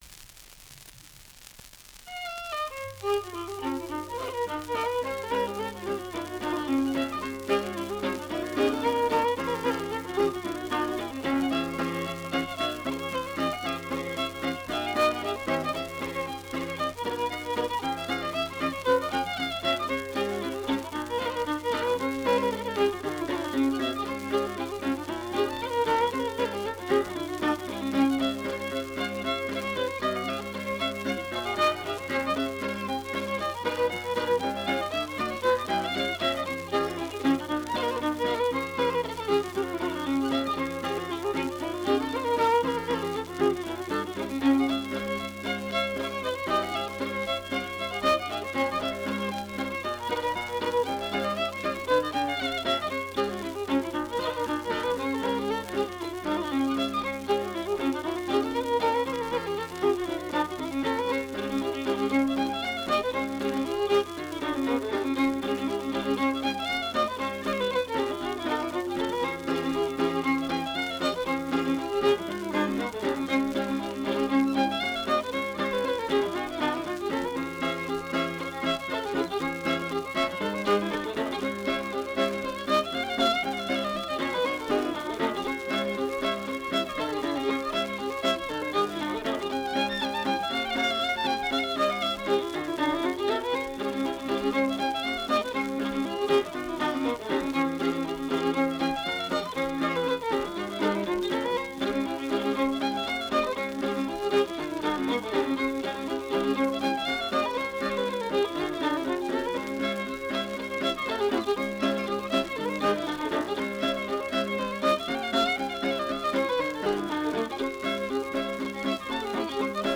Medley of Reels
Violin with Guitar Accompaniment
Keywords: Irish Traditional Music
digitised from an original 78RPM shellac record